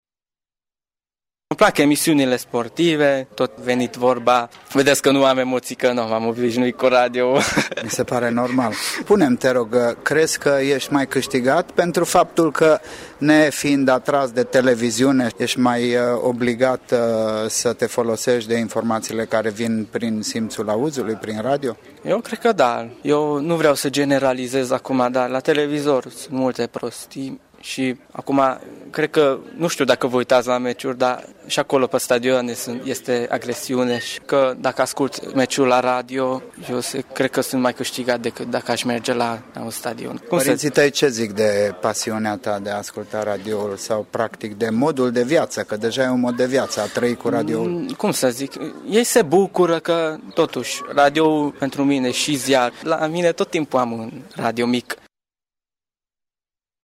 Caravana Bicicleta, un eveniment marca Radio Tîrgu-Mureș, a pornit de la pasiunea pentru biciclete, călătorii și dorința de a-i cunoaște pe prietenii noștri, ascultătorii, acasă la ei.